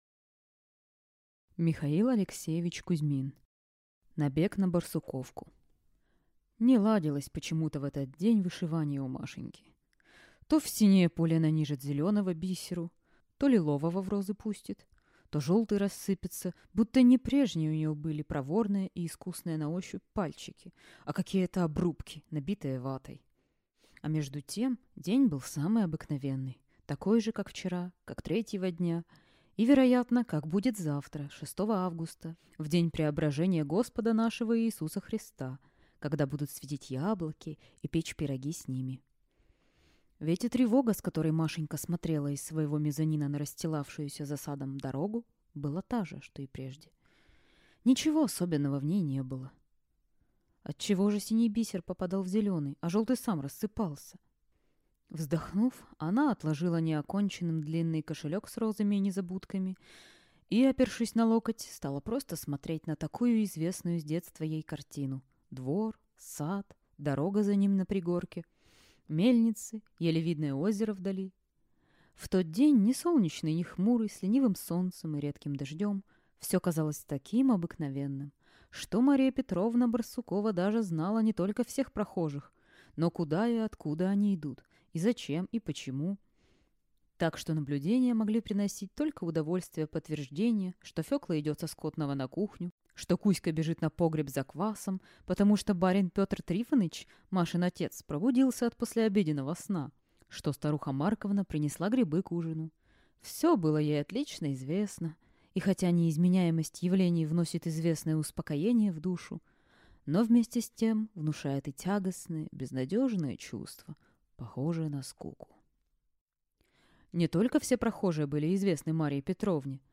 Аудиокнига Набег на Барсуковку | Библиотека аудиокниг